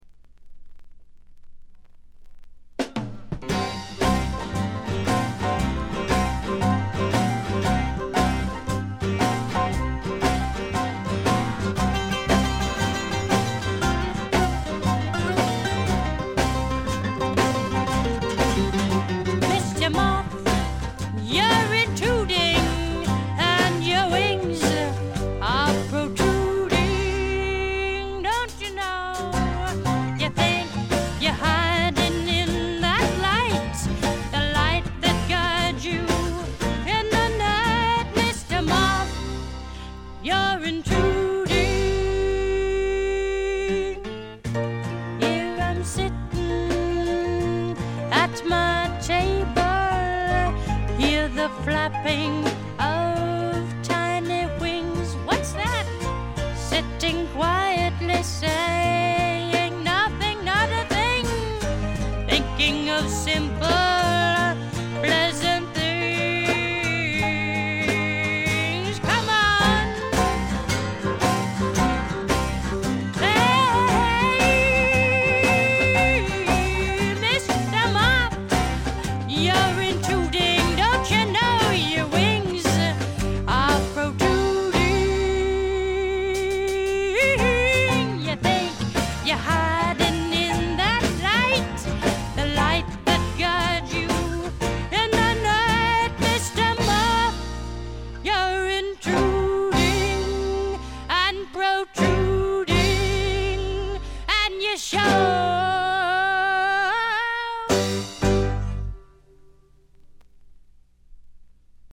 わずかなノイズ感のみ。
試聴曲は現品からの取り込み音源です。
Vocal, Guitar